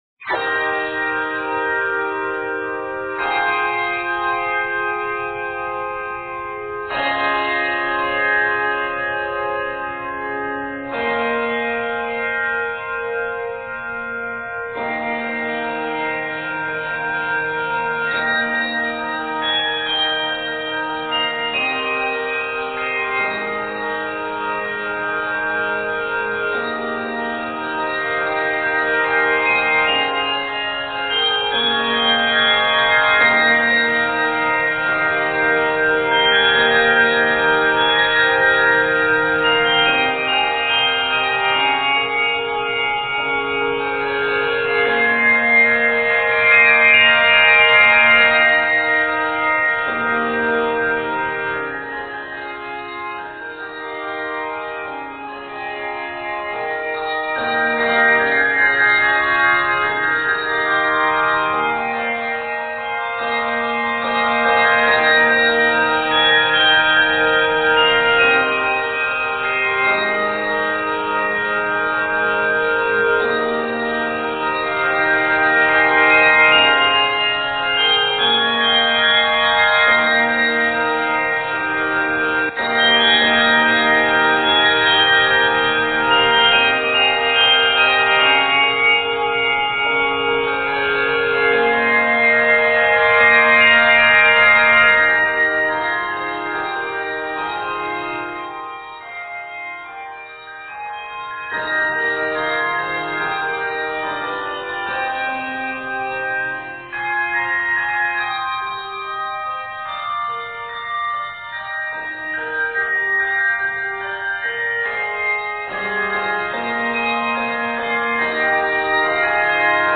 is written in Eb Major and is 128 measures.